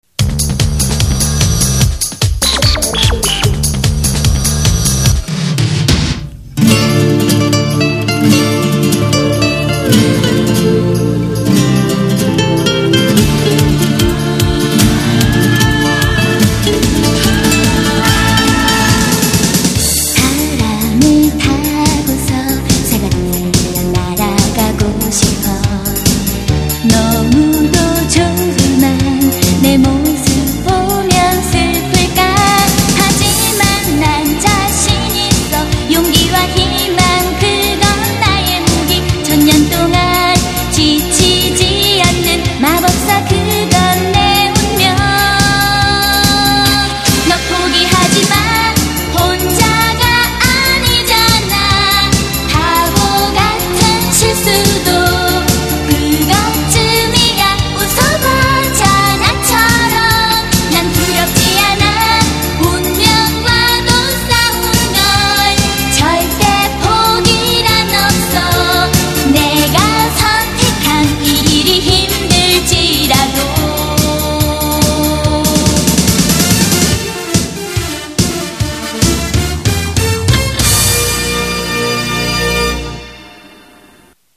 전 이런 리듬의 애니 음악을 즐겨 듣슴다